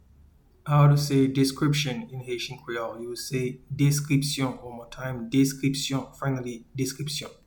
Pronunciation:
Description-in-Haitian-Creole-Deskripsyon.mp3